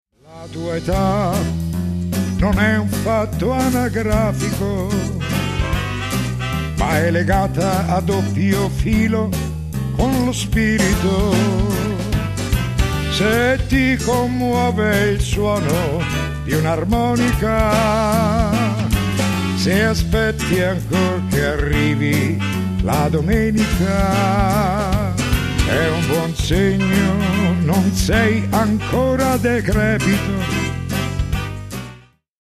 sonorità liriche e orchestrali
ud, darbuka, bandurria...